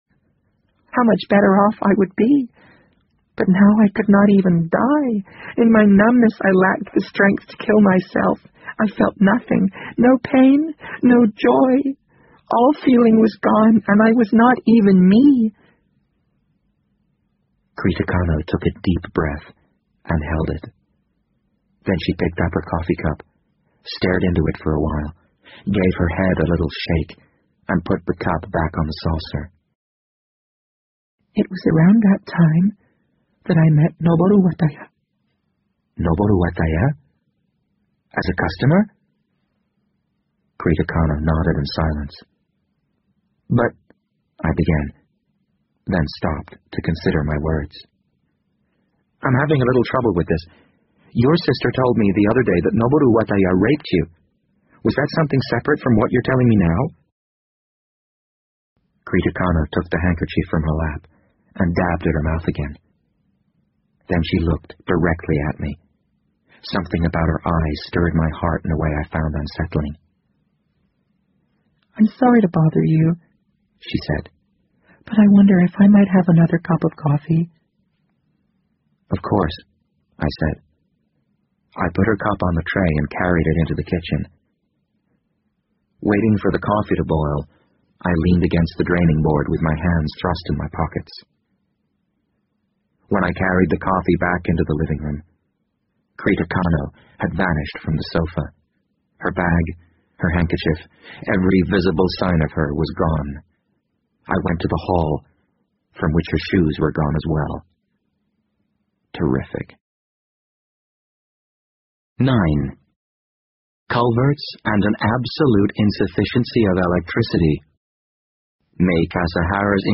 BBC英文广播剧在线听 The Wind Up Bird 50 听力文件下载—在线英语听力室